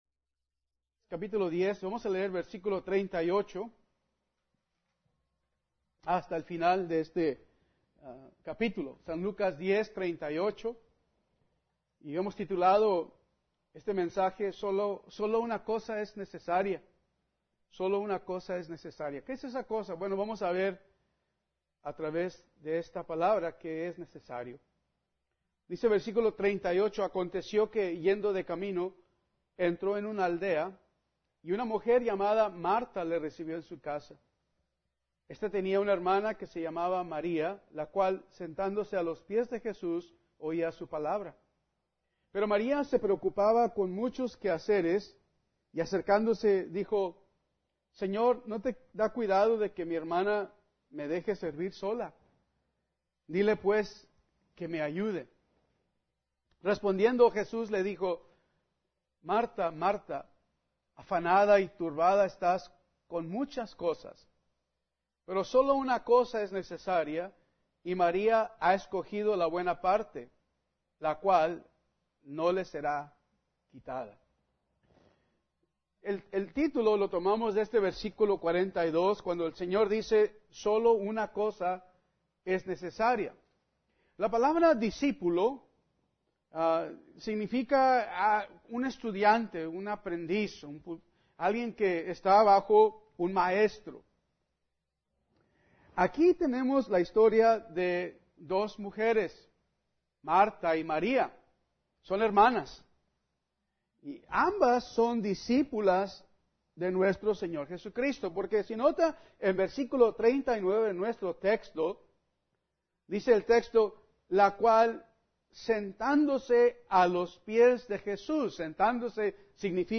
Domingo por la mañana – Lucas 10